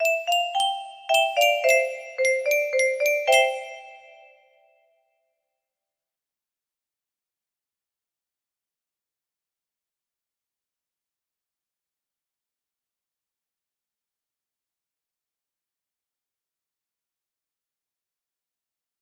Music Box Trial 1 music box melody
A simple short song for testing out a music box